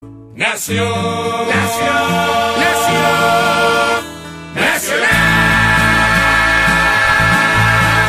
Play, download and share NACIONAL!!!! original sound button!!!!
nacio-de-un-grito-club-nacional-de-football-audiotrimmer.mp3